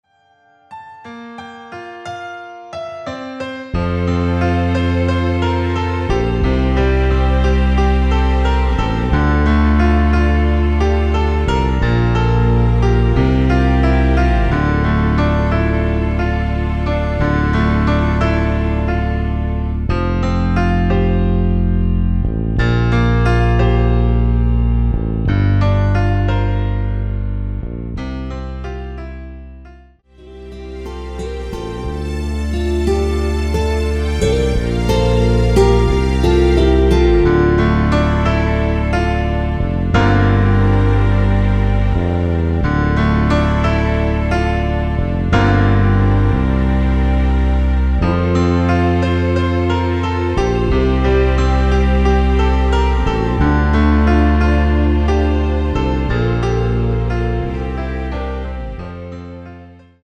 원키에서(+5)올린 MR 입니다.
앞부분30초, 뒷부분30초씩 편집해서 올려 드리고 있습니다.
중간에 음이 끈어지고 다시 나오는 이유는